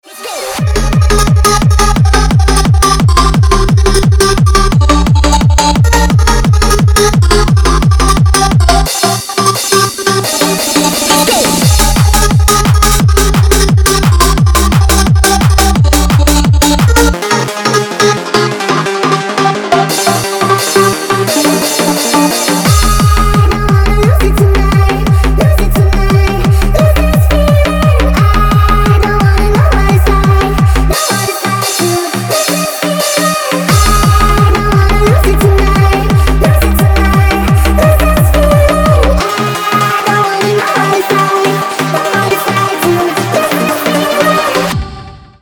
быстрый рингтон